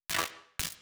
SFX_Static_Electricity_Single_02.wav